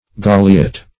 Search Result for " galeate" : The Collaborative International Dictionary of English v.0.48: Galeate \Ga"le*ate\, Galeated \Ga"le*a`ted\, a. [L. galeatus, p. p. of galeare helmet.] 1.
galeate.mp3